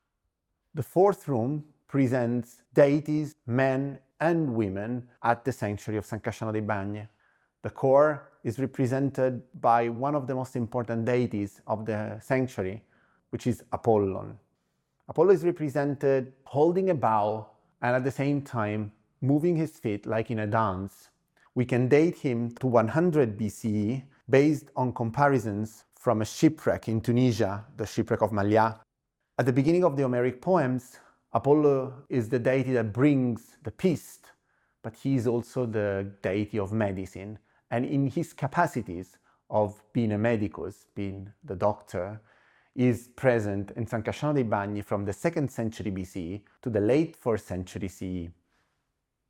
The audioguide dedicated to the exhibition ‘The Gods Return. The Bronzes of San Casciano'”